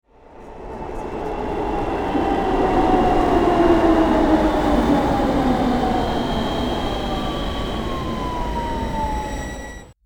Subway Arriving
Subway_arriving.mp3